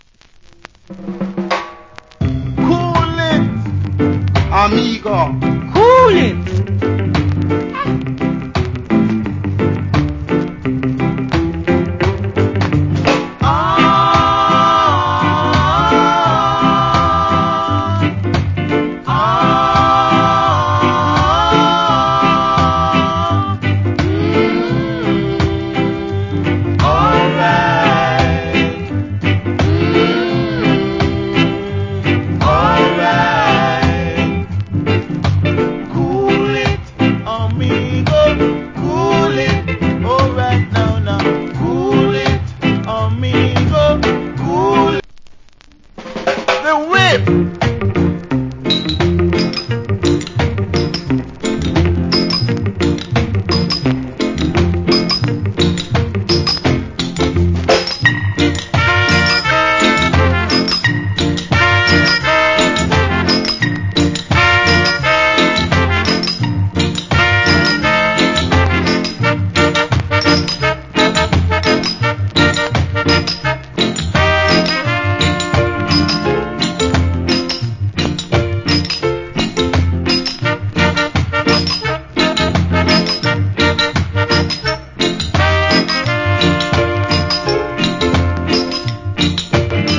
Old Hits Rock Steady.